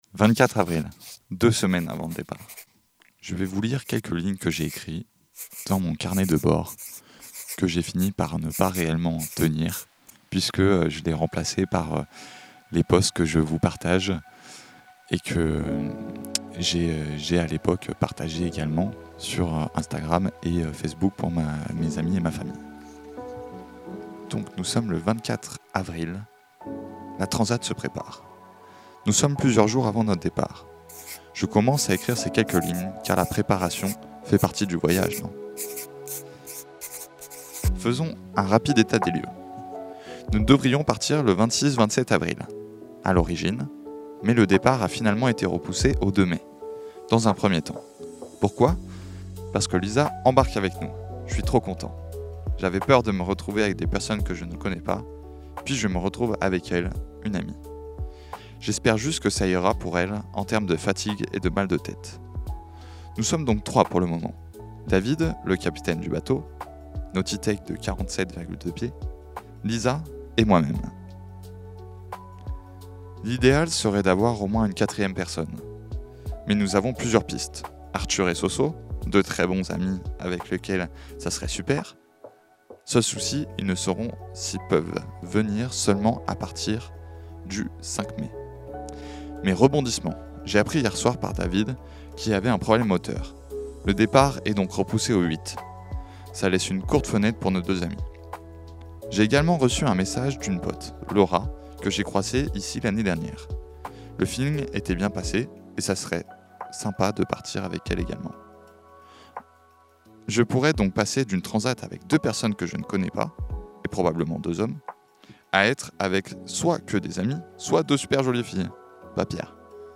Ce podcast est enregistré à Luc-en-diois dans les studios de RDWA. Pour plus de précisions cette introduction a été enregistrée après l’épisode 1 et 2, donc si jamais vous trouvez un faux-raccord vous savez pourquoi !